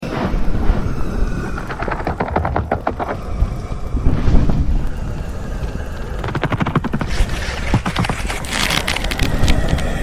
Звук коллапса пирокластической аномалии